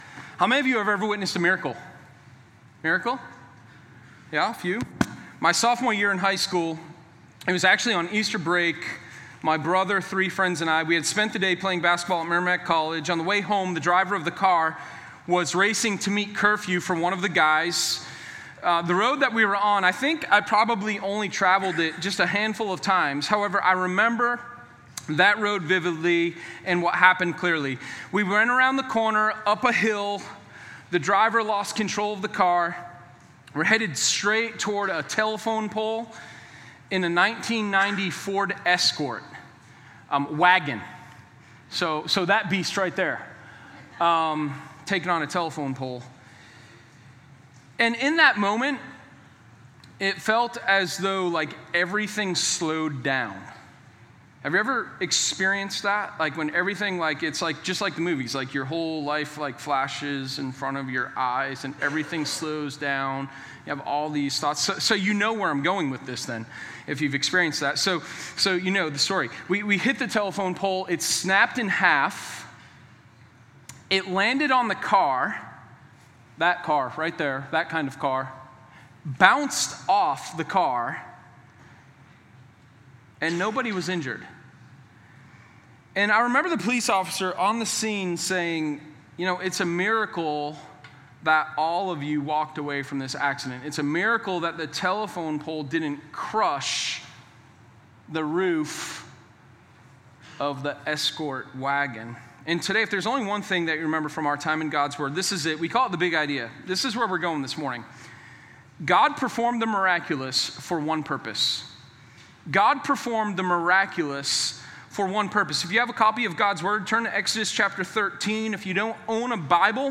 Sermon0401_RedSeaResurrection.mp3